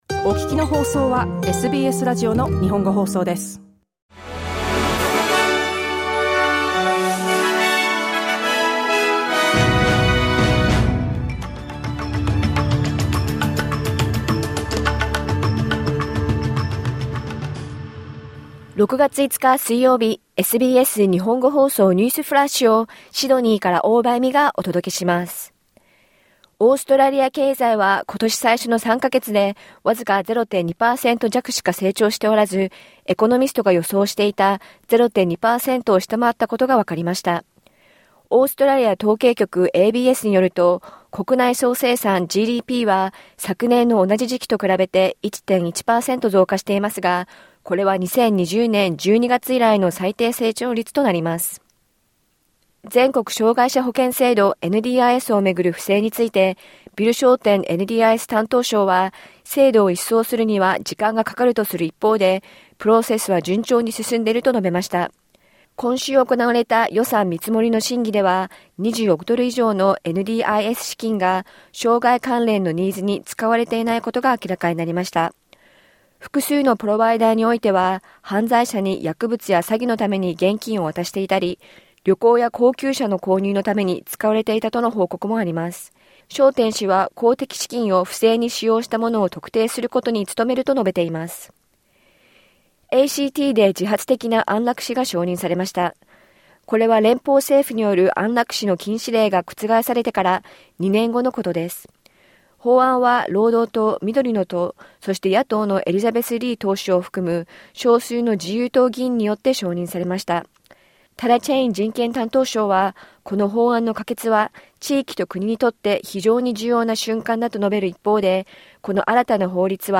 SBS日本語放送ニュースフラッシュ 6月5日水曜日